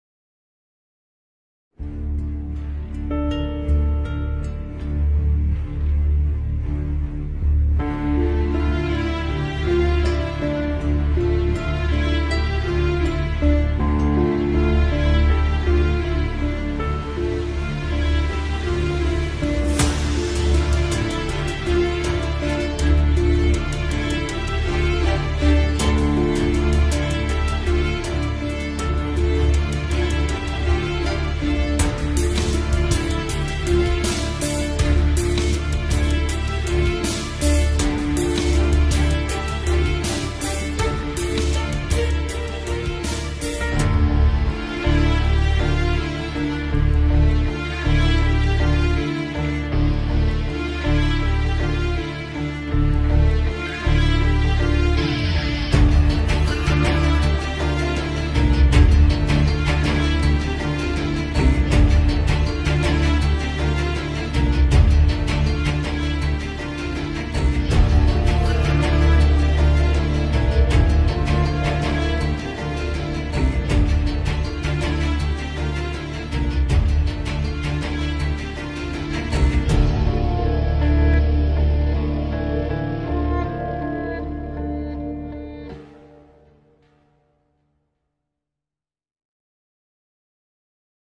tema dizi müziği, duygusal heyecan gerilim fon müziği.